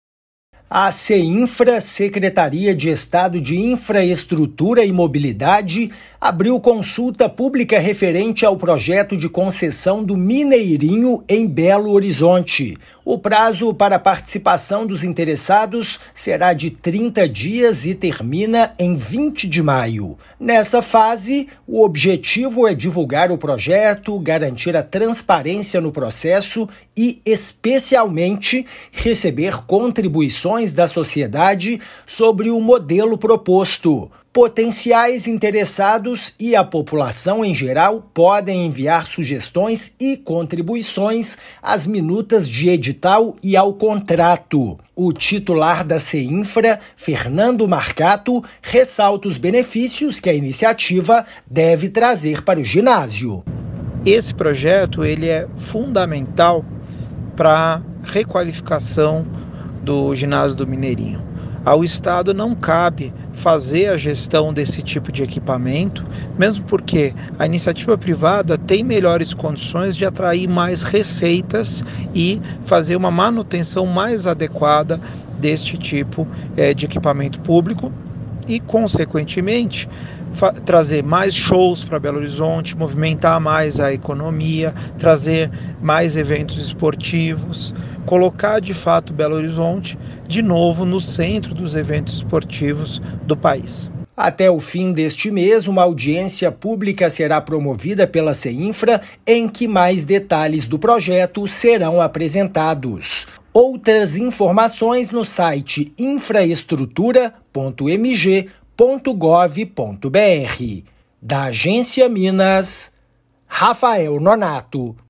[RÁDIO] Consulta pública sobre concessão do Mineirinho começa nesta terça-feira (20/4)
Objetivo é divulgar o projeto, garantir transparência no processo e receber contribuições da sociedade. Ouça a matéria de rádio.
MATÉRIA_RÁDIO_CONSULTA_PÚBLICA_MINEIRINHO.mp3